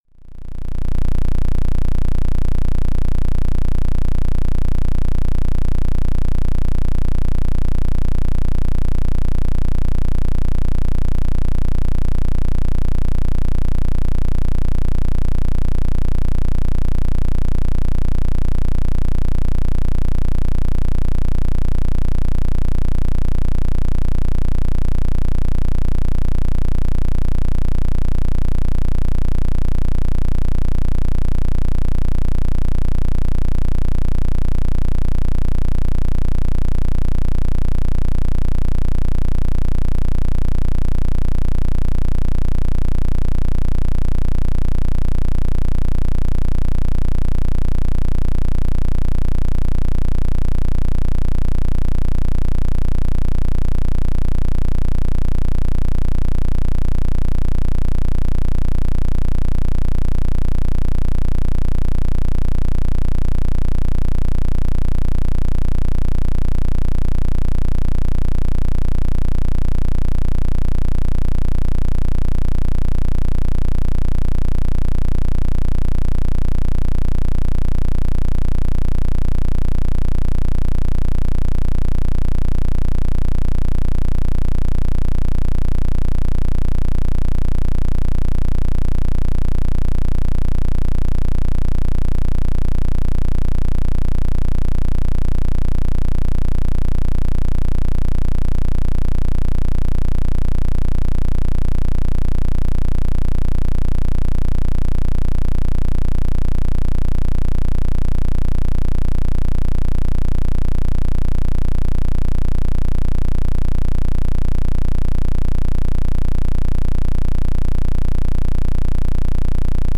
10. PEMF 15 Hz - Healthier Skin, Osteoporosis, Blood Circulation Square.mp3